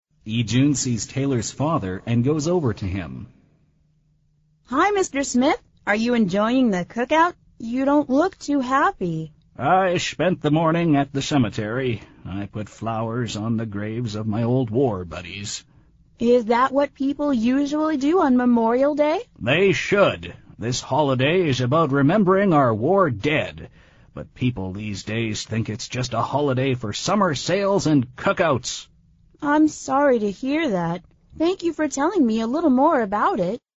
美语会话实录第202期(MP3+文本):Remember our war dead!